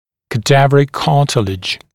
[kə’dævərɪk ‘kɑːtɪlɪʤ][кэ’дэвэрик ‘ка:тилидж]хрящ трупа